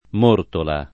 Mortola [ m 1 rtola ]